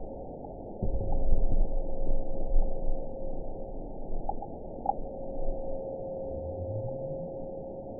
event 912616 date 03/30/22 time 10:15:14 GMT (3 years, 1 month ago) score 9.65 location TSS-AB03 detected by nrw target species NRW annotations +NRW Spectrogram: Frequency (kHz) vs. Time (s) audio not available .wav